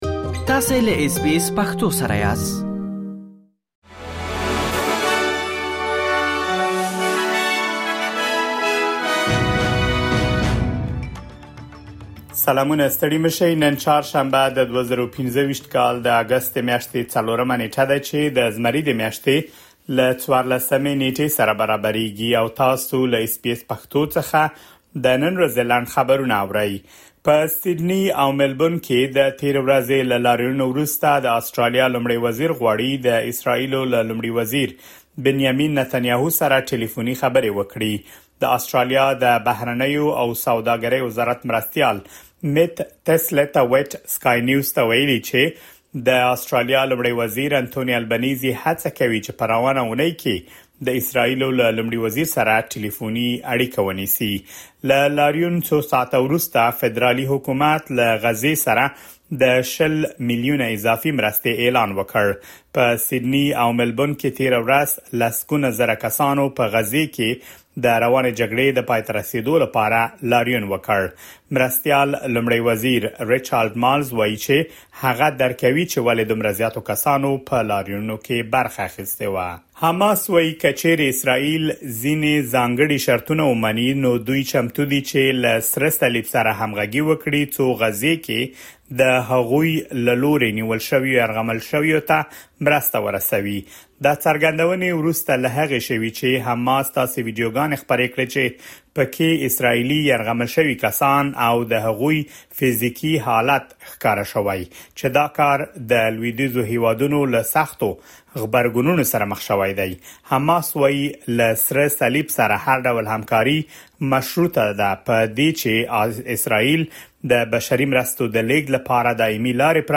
د اس بي اس پښتو د نن ورځې لنډ خبرونه |۴ اګسټ ۲۰۲۵